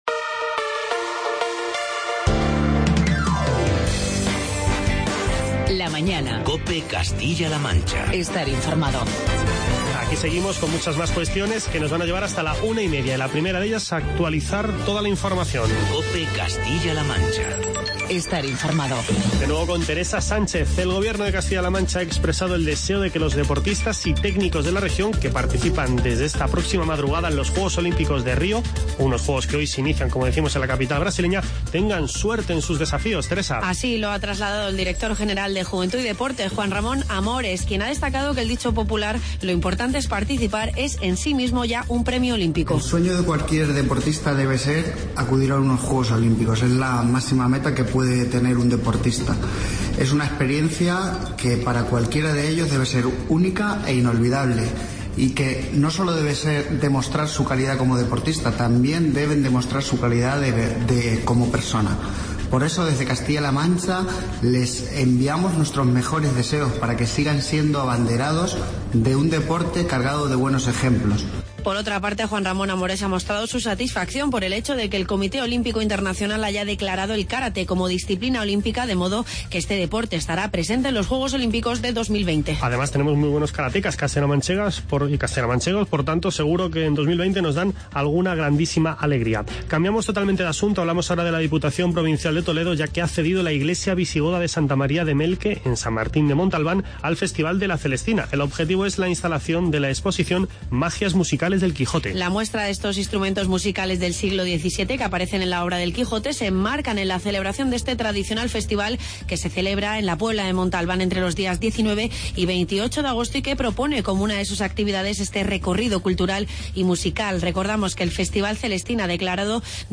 Entrevista con la alcaldesa de Socuéllamos, Pruden Medina.